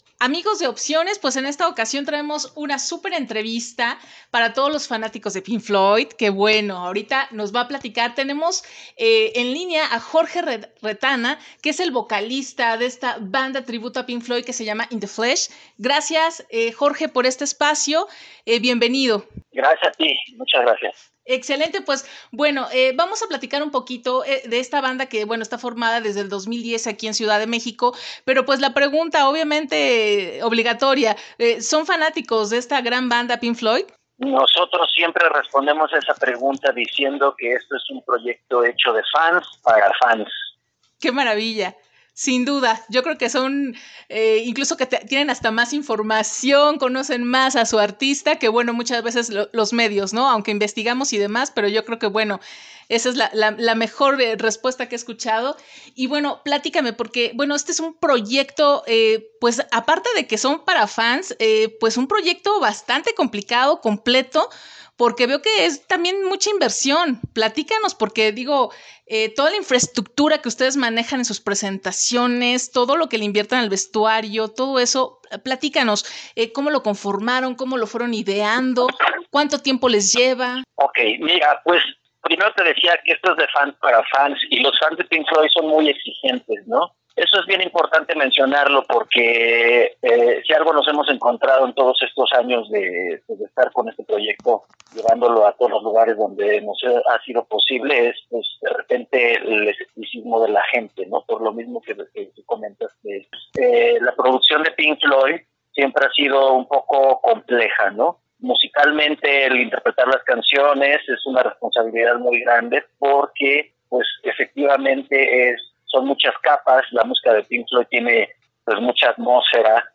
Entrevista_Edit_Pink_Floyd_Mayo2022.mp3